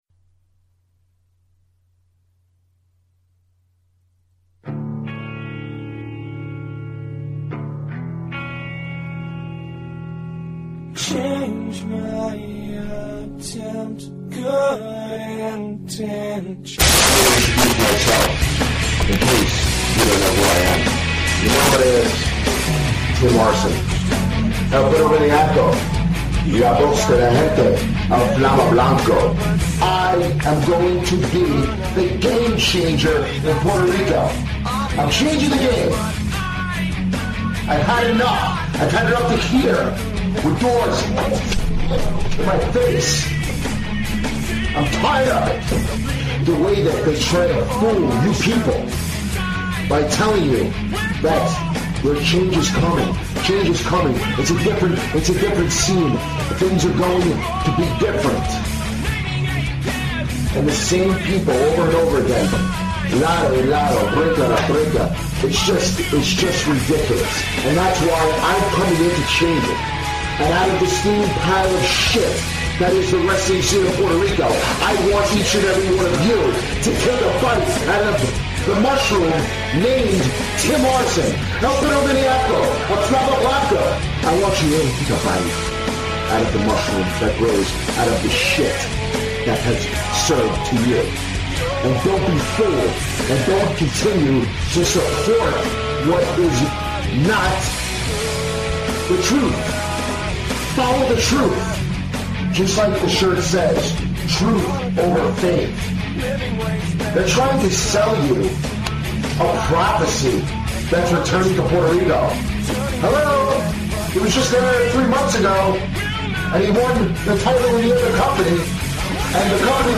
The boys return to LIVE broadcasting for 2015